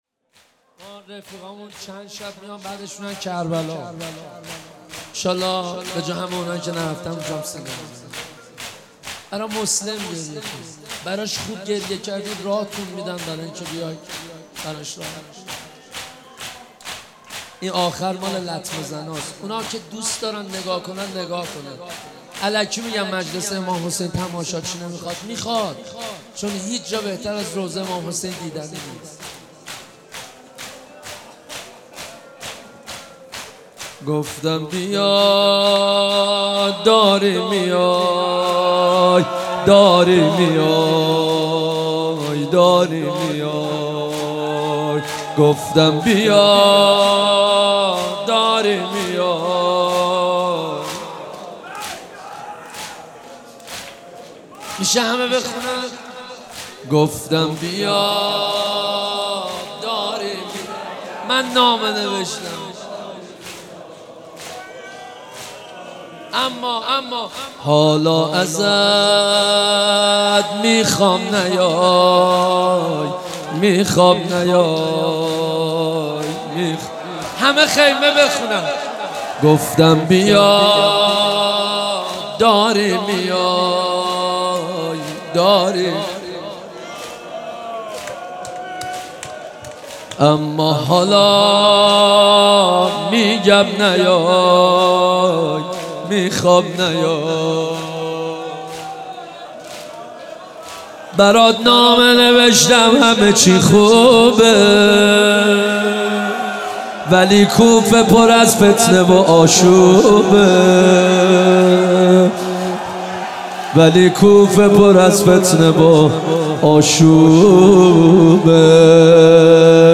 مناسبت : شب اول محرم